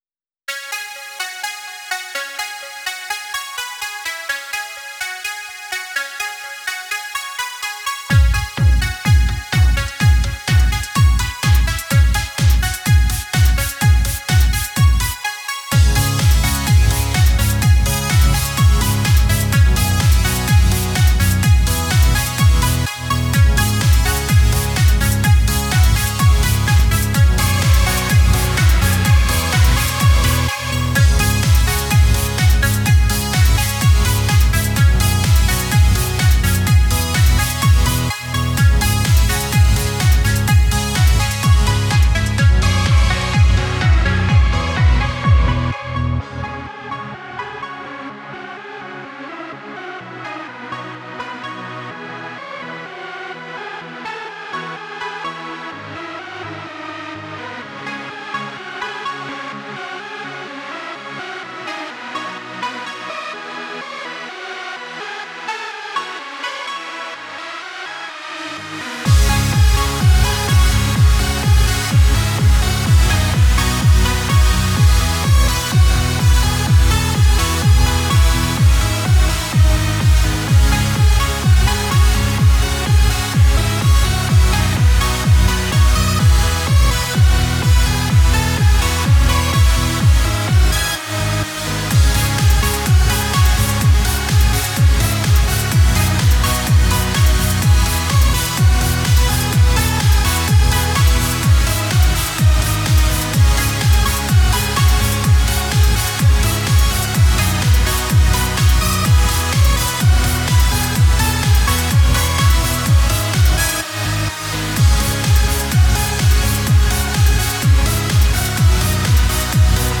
ELECTRO G-N (38)